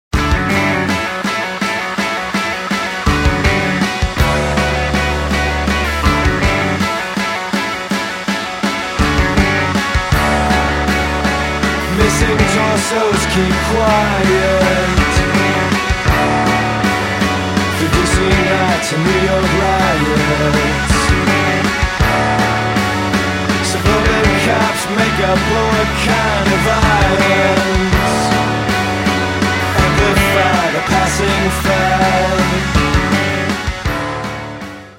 My favorite fast song